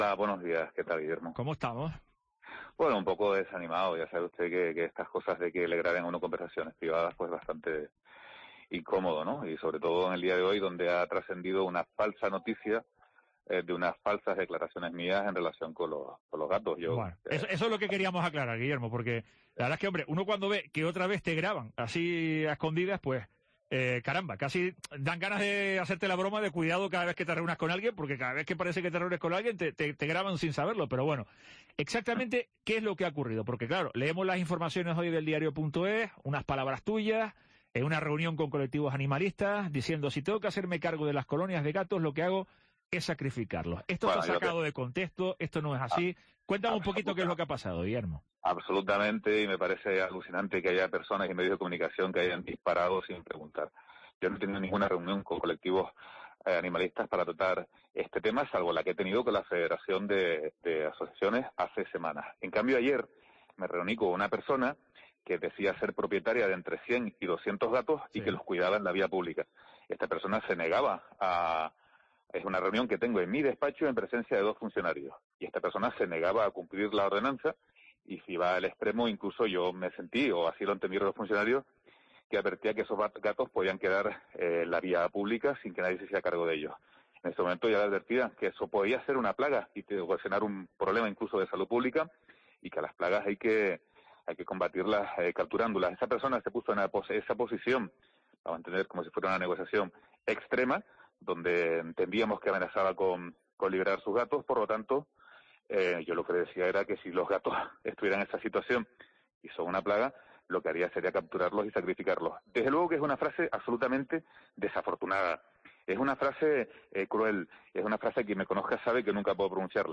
El concejal de Servicios Públicos del Ayuntamiento de Santa Cruz Guillermo Díaz Guerra ha ofrecido su versión en La Mañana de COPE Tenerife tras la publicación en las últimas horas de una grabación en la que afirma que estaría dispuesto a sacrificar algunas colonias de gatos en la capital.